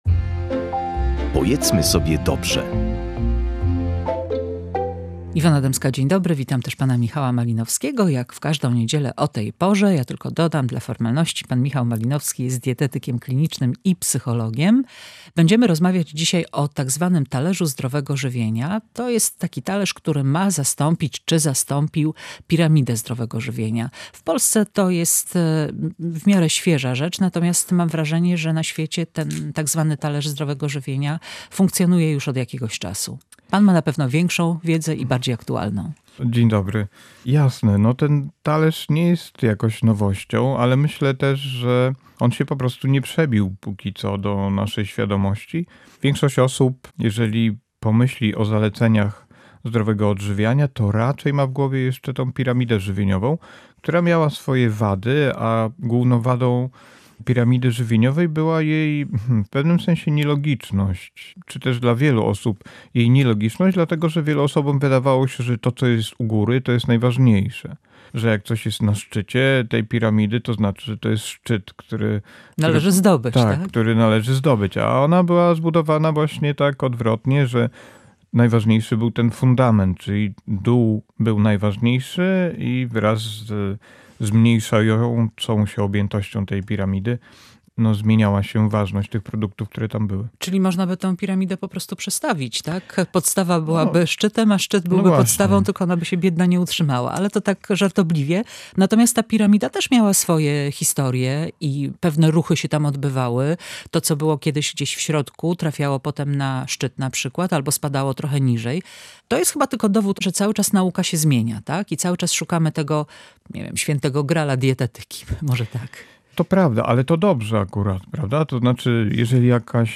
W audycji "Pojedzmy sobie dobrze" rozmawialiśmy o piramidzie żywienia i talerzu żywieniowym. Jakie są między nimi podobieństwa, a jakie różnice?